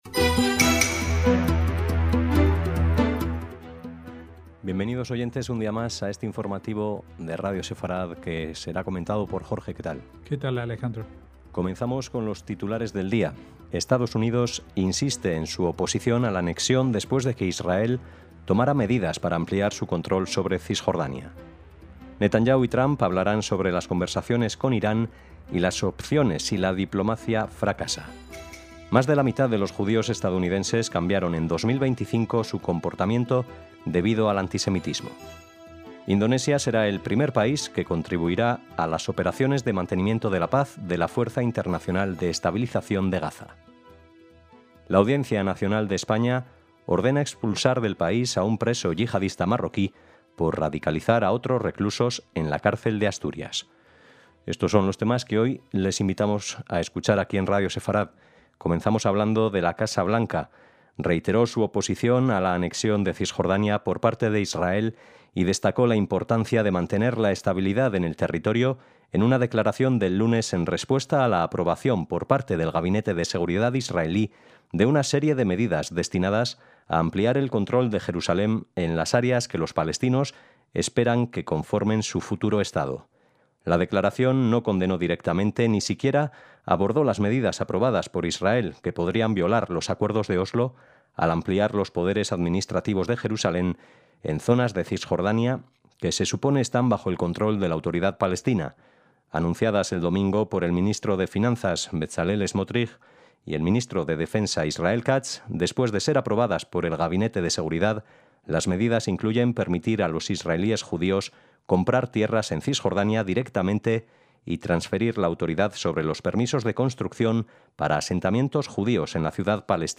NOTICIAS - Titulares de hoy: EE.UU. insiste en su oposición a la anexión después de que Israel tomara medidas para ampliar su control sobre Cisjordania (en la imagen, un asentamiento). Netanyahu y Trump hablarán sobre las conversaciones con Irán y las opciones si la diplomacia fracasa. Más de la mitad de los judíos estadounidenses cambiaron en 2025 su comportamiento, debido al antisemitismo.